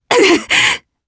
Demia-Vox_Damage_jp_02.wav